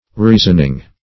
Reasoning \Rea"son*ing\, n.